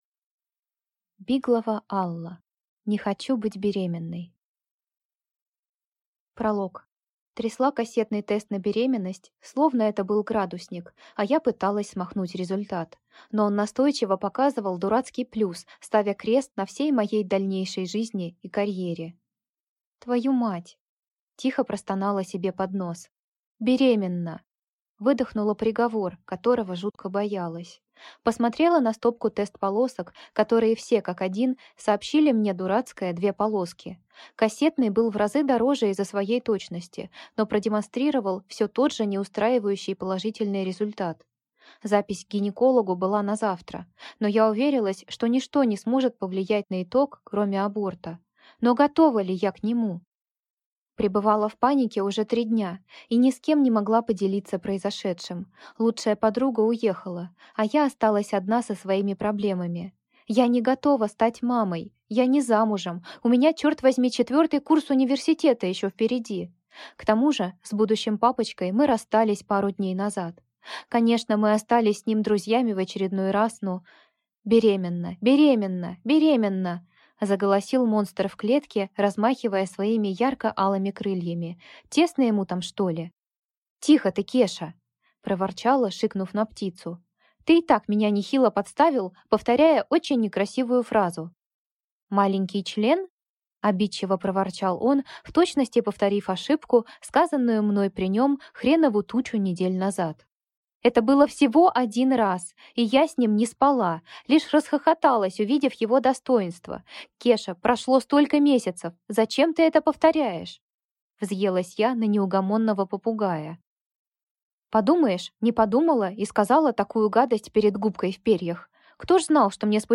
Аудиокнига Не хочу быть беременной!